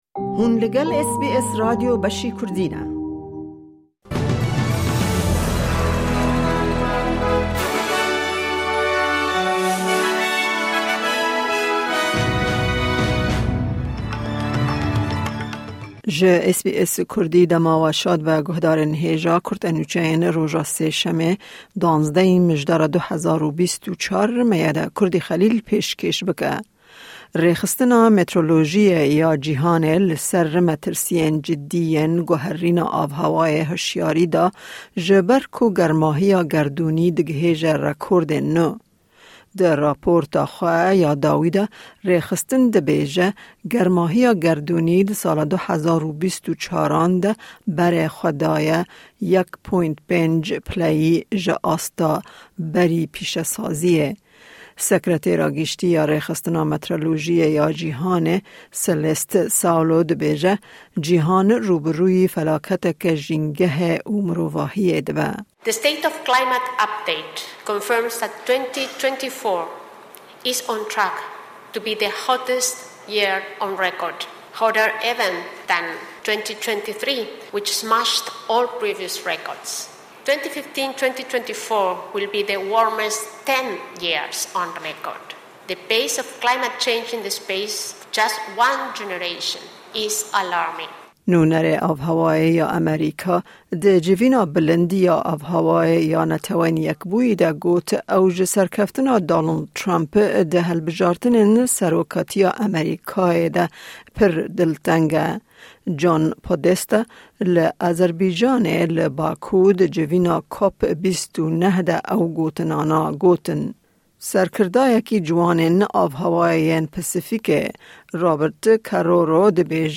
Kurte Nûçeyên roja Sêşemê 12î Mijdara 2024